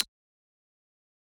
key-press-3.mp3